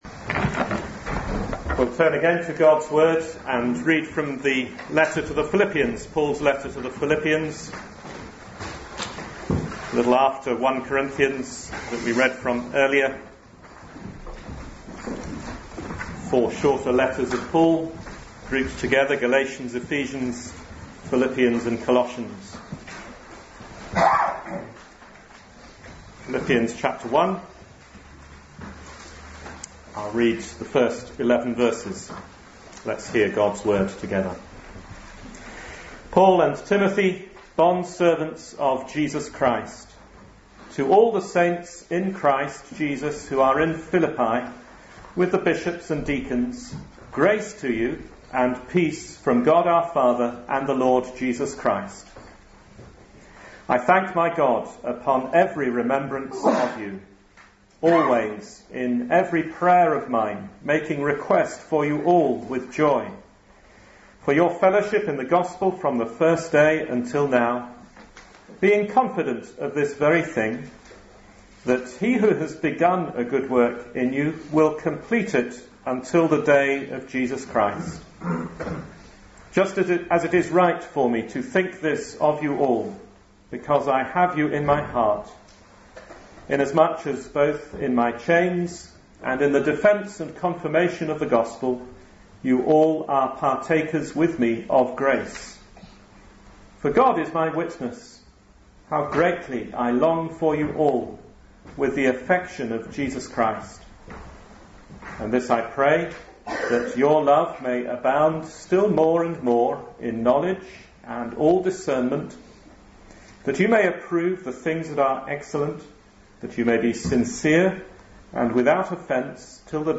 2013 Service Type: Sunday Morning Speaker